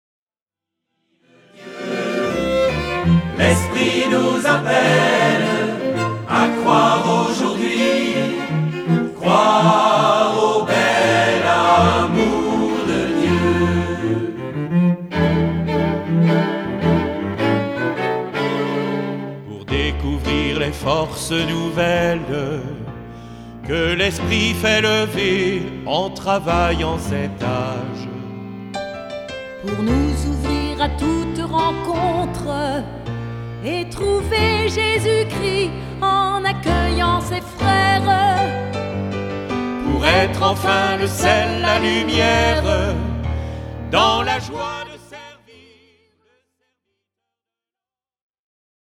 ) sont servis par des instruments à cordes et piano.